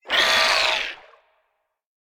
File:Sfx creature triops death 01.ogg - Subnautica Wiki
Sfx_creature_triops_death_01.ogg